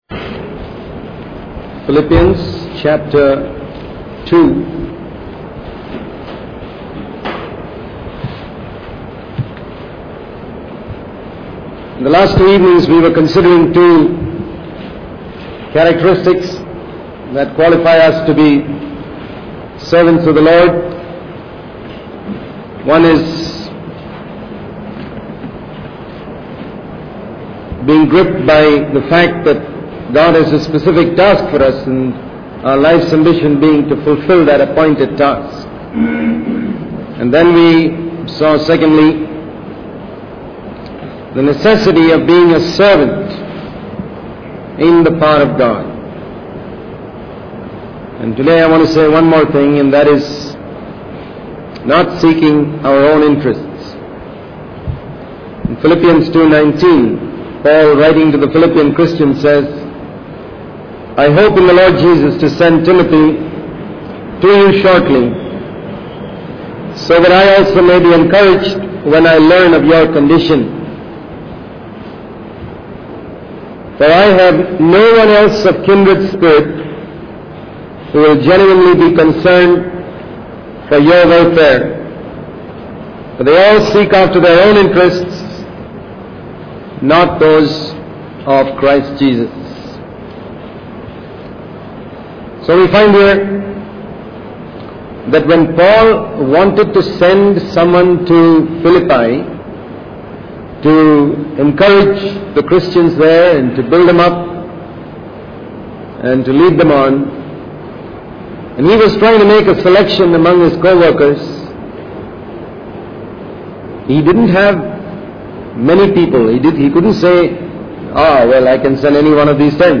In this sermon, the speaker emphasizes the importance of being responsible and alert when leading a meeting.